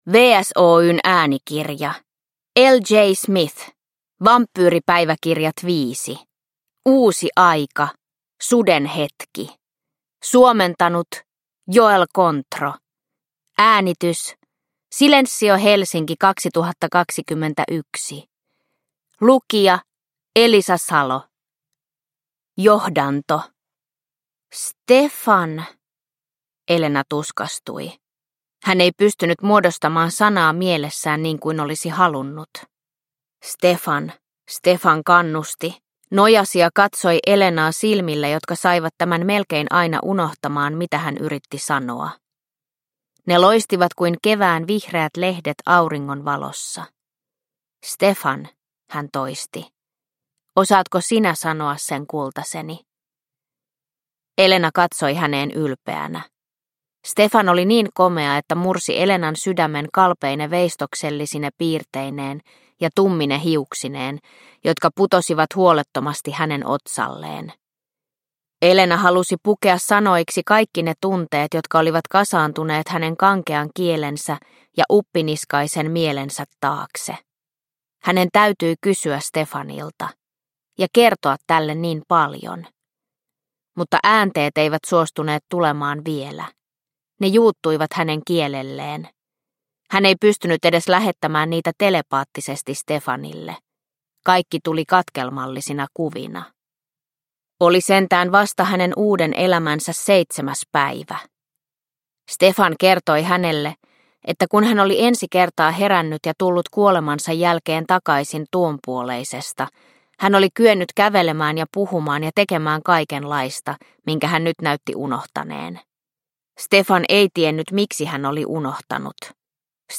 Uusi aika: Suden hetki – Ljudbok – Laddas ner